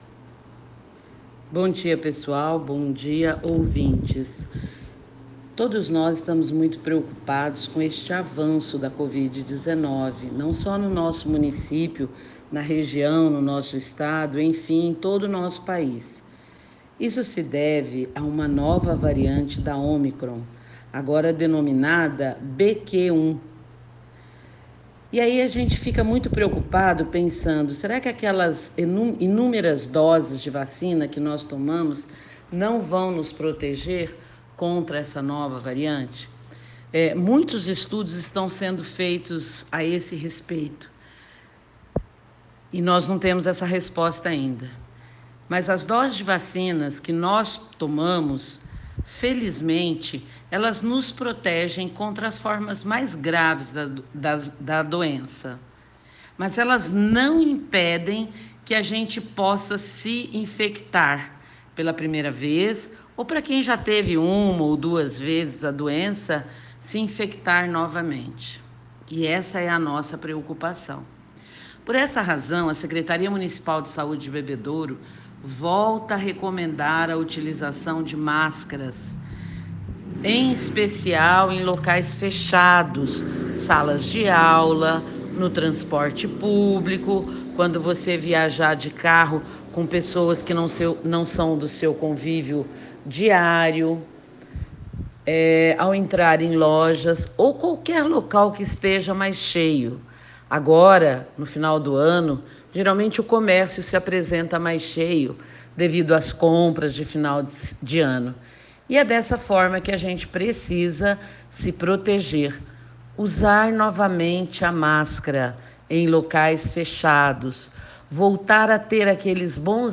Ouça aqui a entrevista com a secretária de Saúde, Silvéria Lâredo.